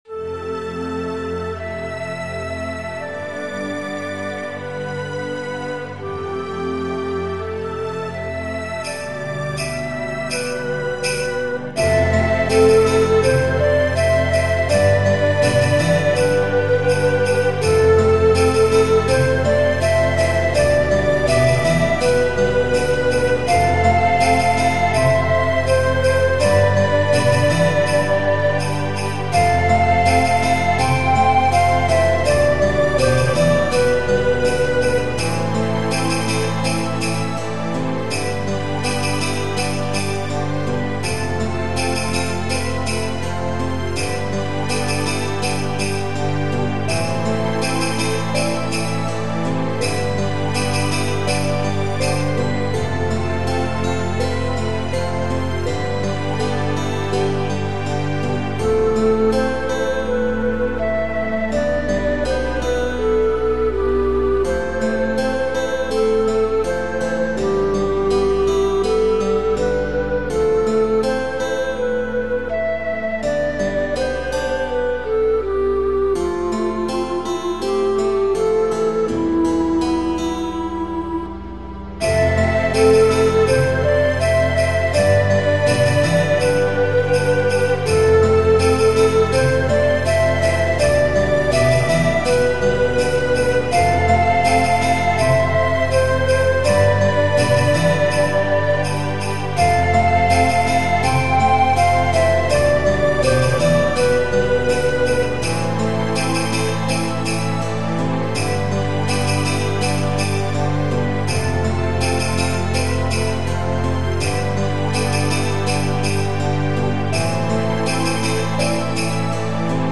ほとんどソフトウェア音源だけで作成してます。
メロディーが日本的って言われた。